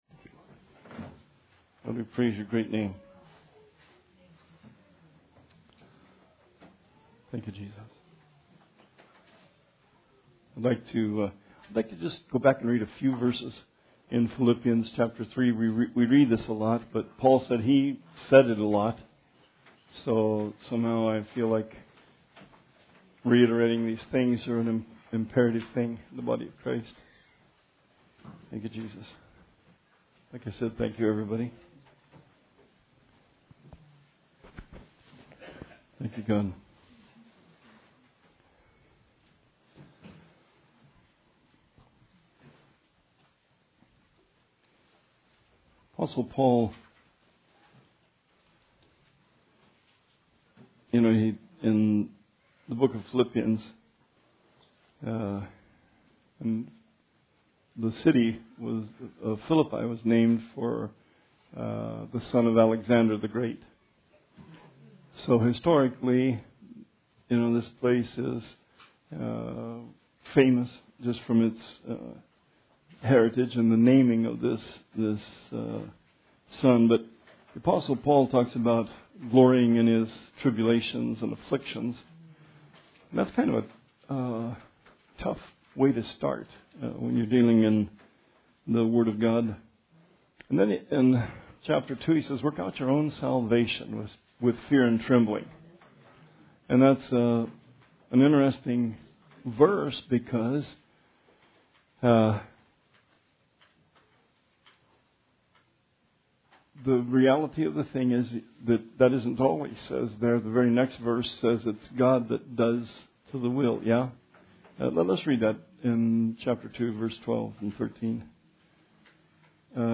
Bible Study